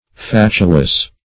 Fatuous \Fat"u*ous\, a. [L. fatuus.]